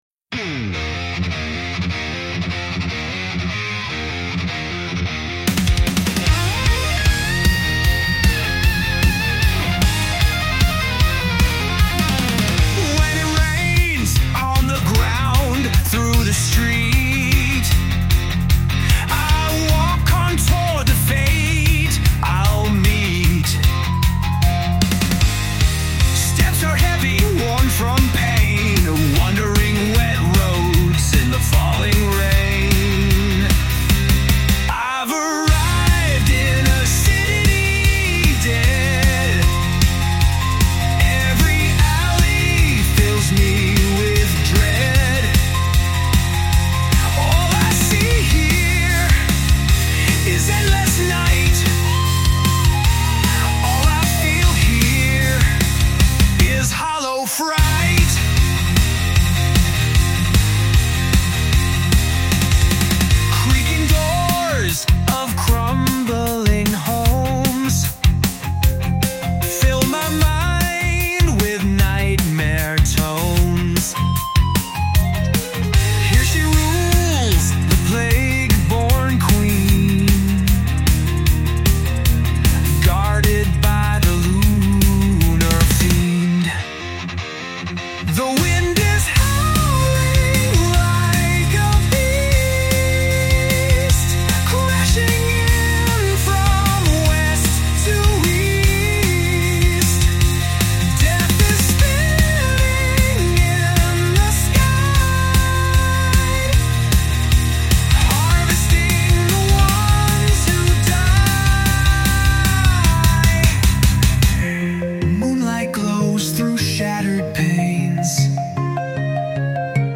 English, Metal, Rock | 15.04.2025 17:58